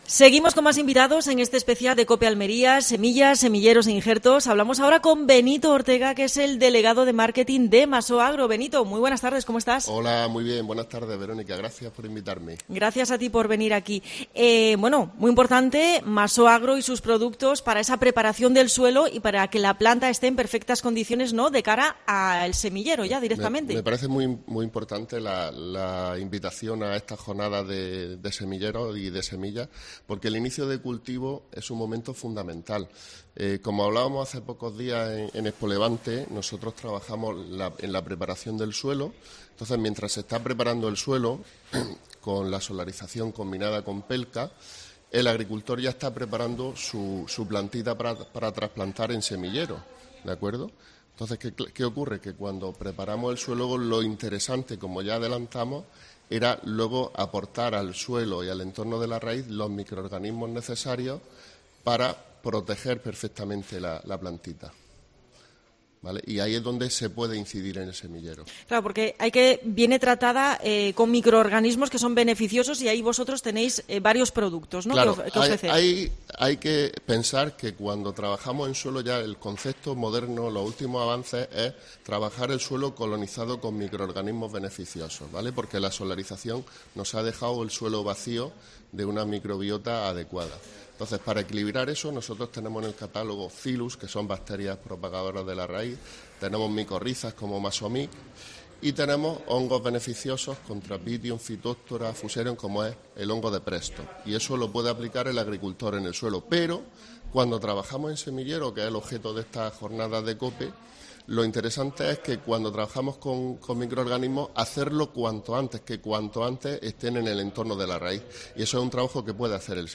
Programación especial de COPE Almería desde Tecnobioplant.